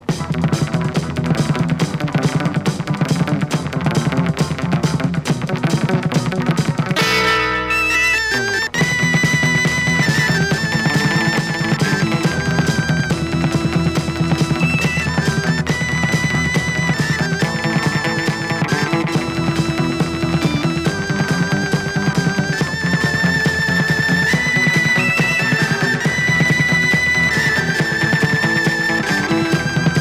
Rock instrumental Premier EP retour à l'accueil